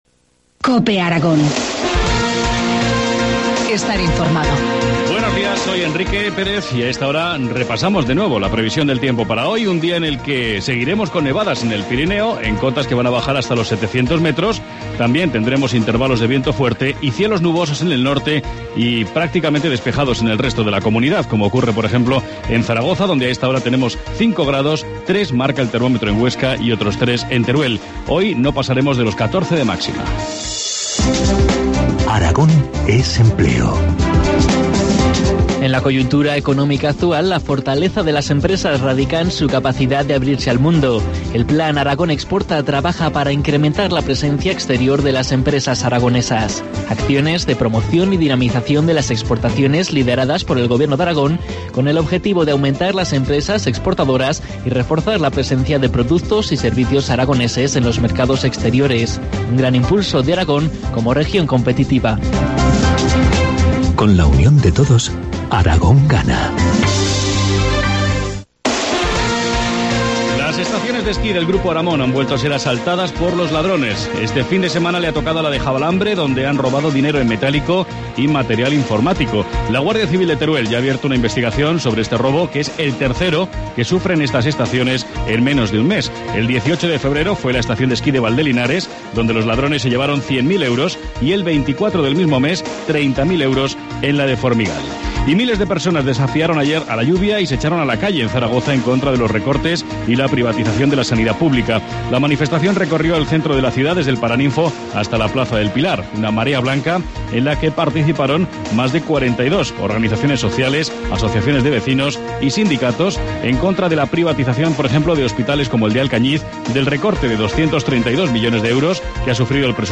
Informativo matinal, lunes 18 de marzo, 8.25 horas